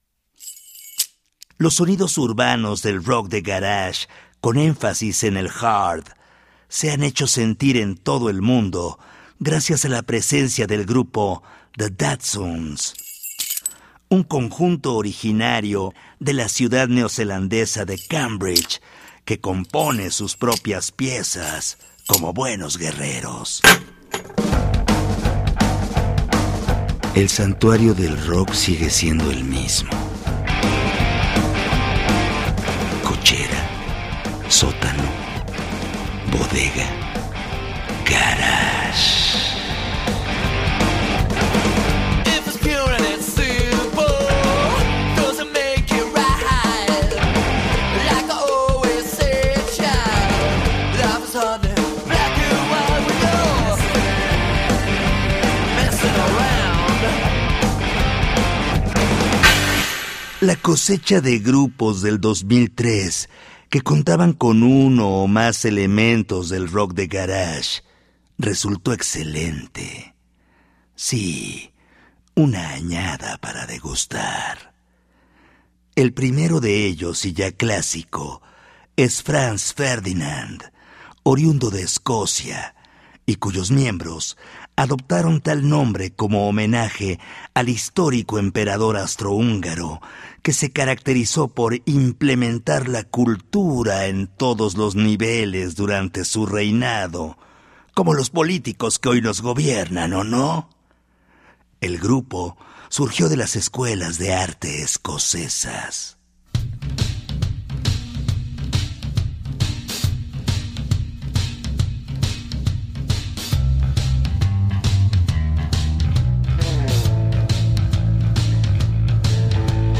Garage Rock del Siglo XXI